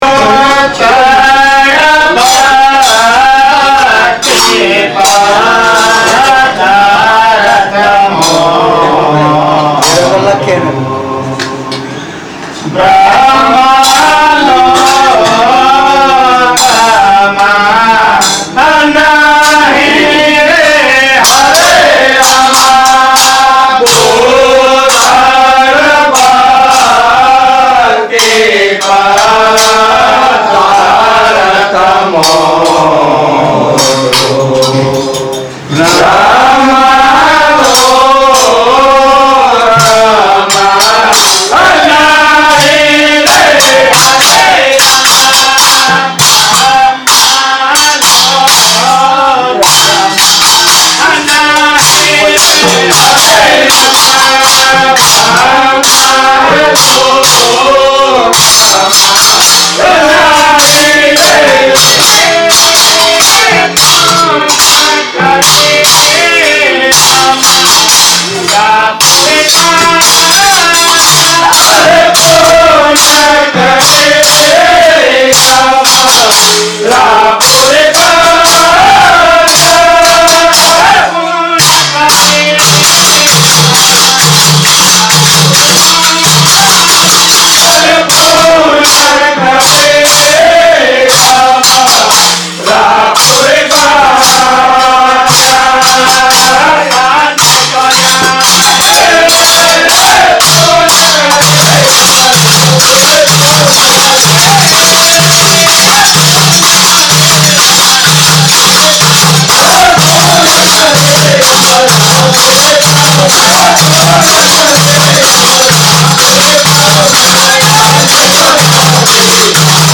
Ramkabir Bhajans ( RAMKABIR BHAJAN રામકબીર ભજન ) is dedicated to the traditional bhajans of Shree Ramkabir Bhakta Samaj.